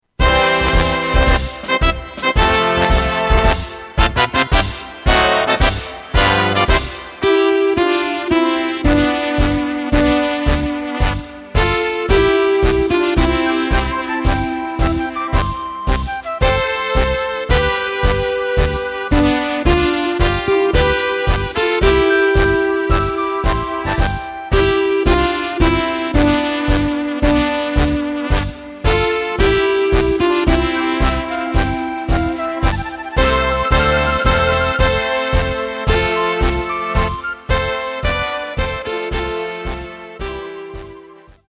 Takt:          2/4
Tempo:         120.00
Tonart:            C
Polka Blasmusik!